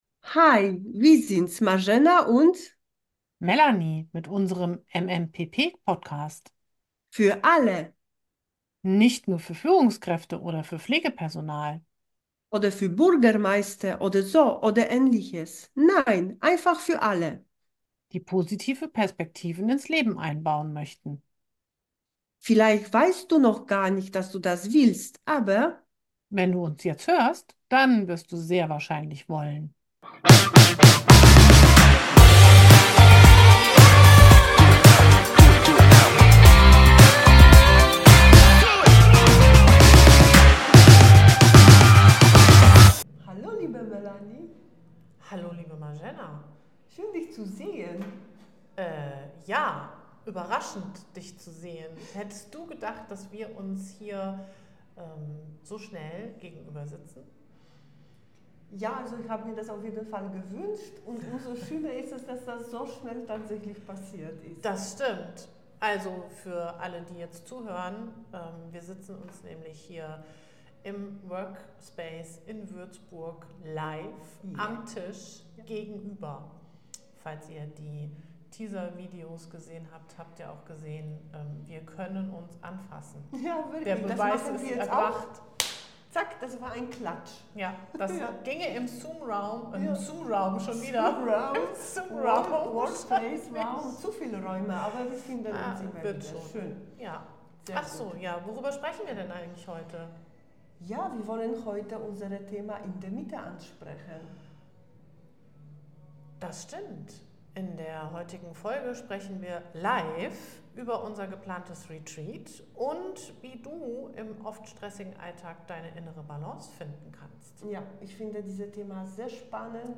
In dieser besonderen Folge von Positive Perspektiven sprechen wir live darüber, was es bedeutet in der persönlichen Mitte zu sein. Wie finden wir dorthin – und vor allem, wie bleiben wir dort?